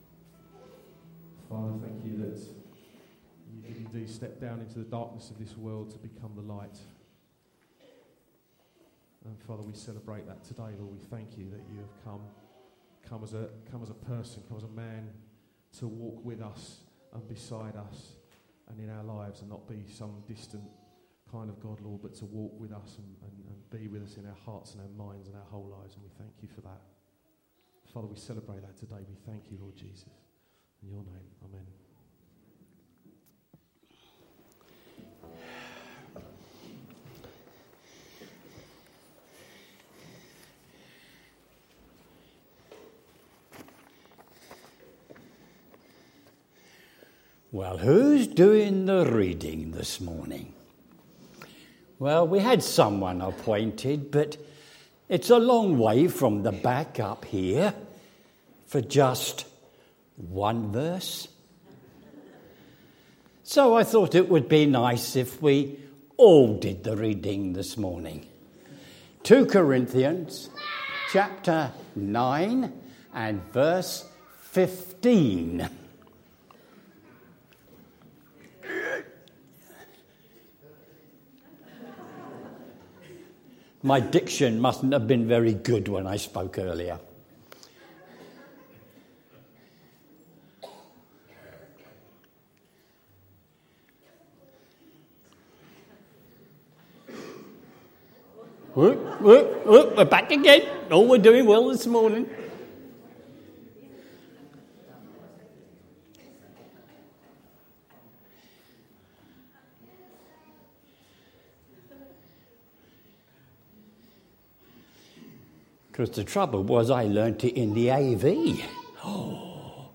A message from the series "2 Corinthians."